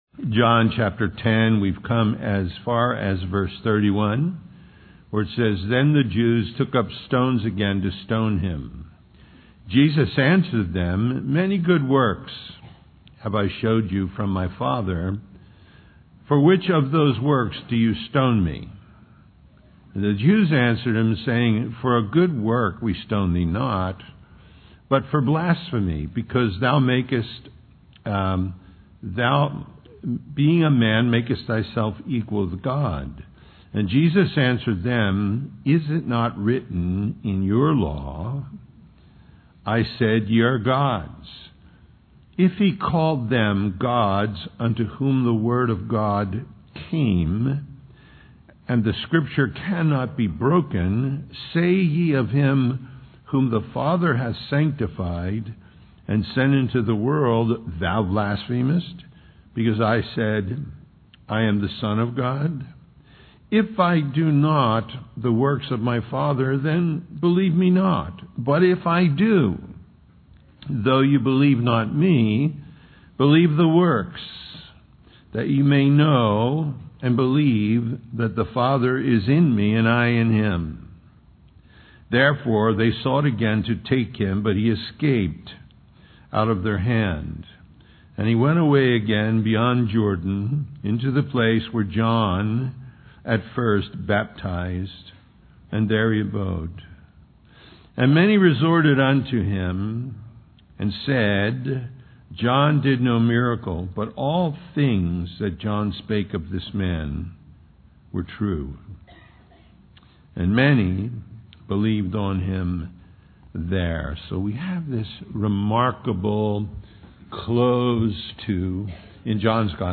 John 10:31-10:42 Ultimate Decision Listen Download Original Teaching Email Feedback 10 Then the Jews took up stones again to stone him.